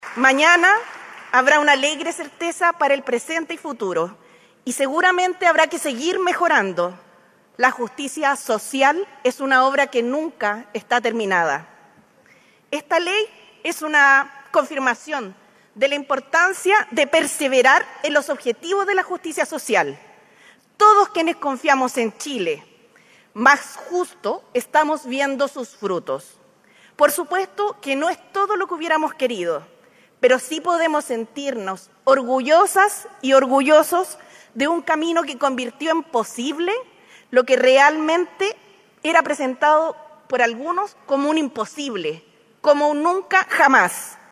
La actividad se realizó el jueves 20 de marzo en el Centro Cultural y Deportivo Chimkowe de Peñalolén.